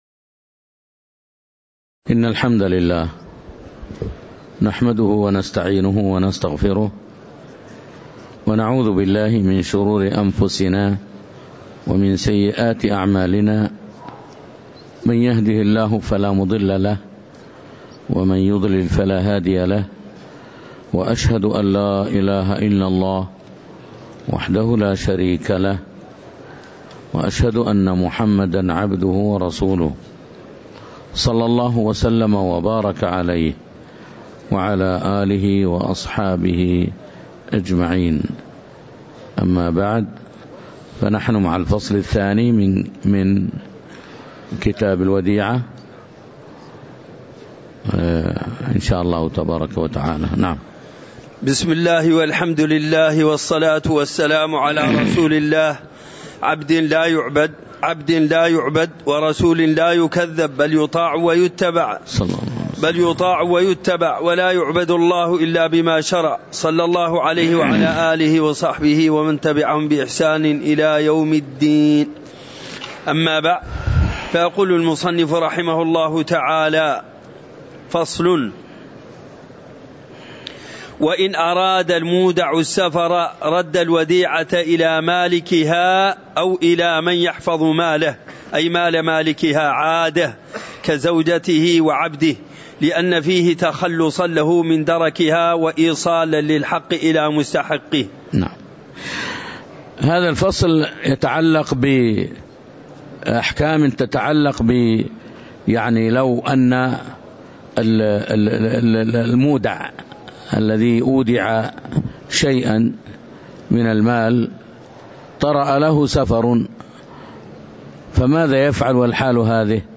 تاريخ النشر ١٥ ذو القعدة ١٤٤٣ هـ المكان: المسجد النبوي الشيخ